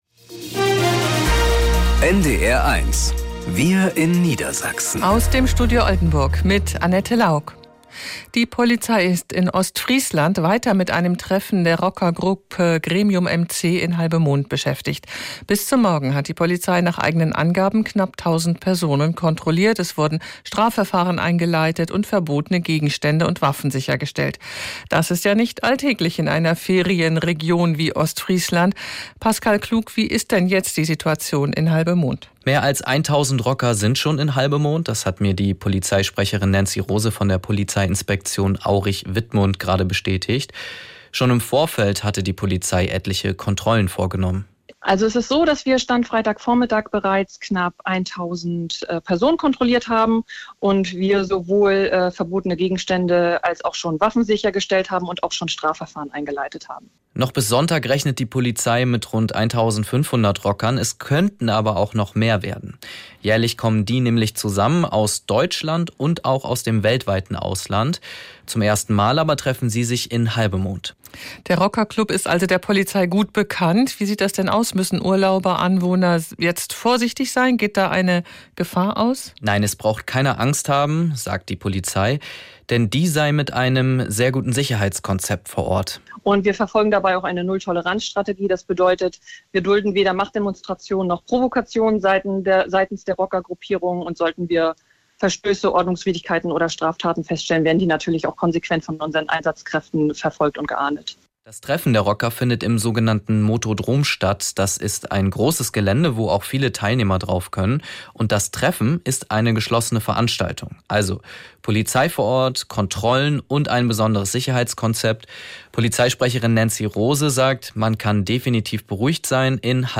… continue reading 4 епізоди # Tägliche Nachrichten # Nachrichten # NDR 1 Niedersachsen